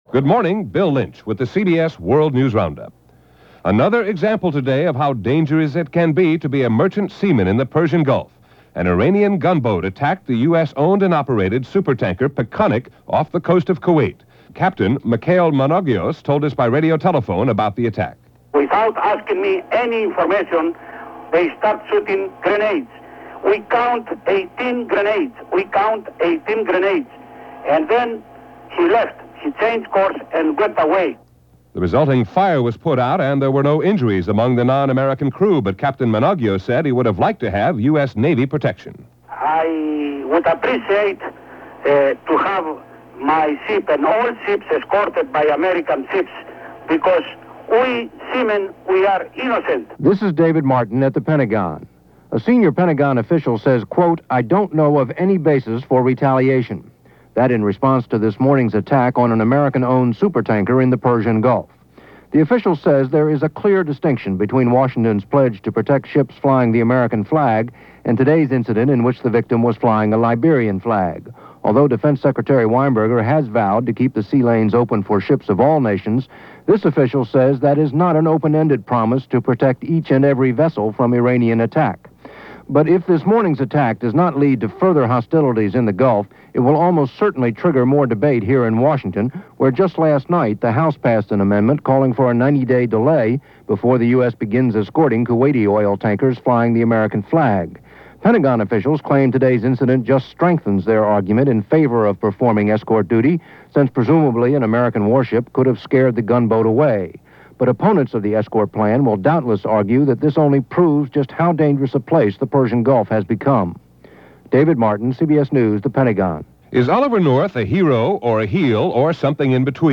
And that’s a small slice of what went on this July 9th in 1987 as reported by The CBS World News Roundup.